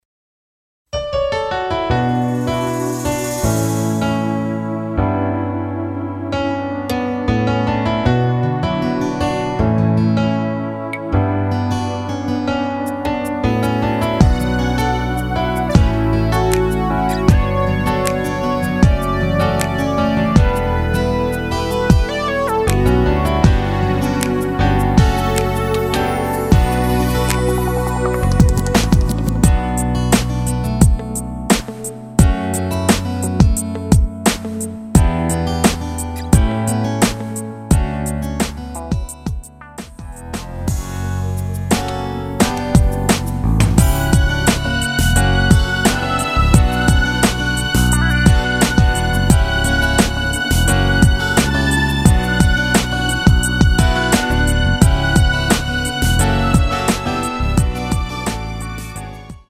키 Bm
원곡의 보컬 목소리를 MR에 약하게 넣어서 제작한 MR이며